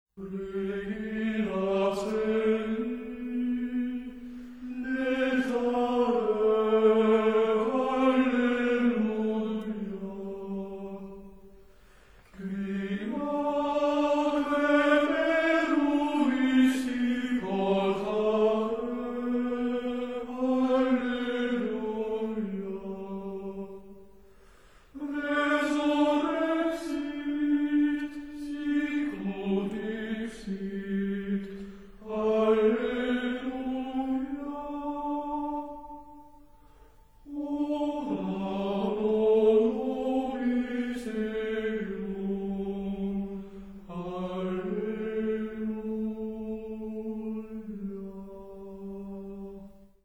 Choralschola der Wiener Hofburgkapelle – Gregorian Chants For All Seasons [2 CD]
Григорианские песнопения годичного богослужебного круга в исполнении школы хорала при Венской придворной капелле Хофбурга (Австрия). 1990 г.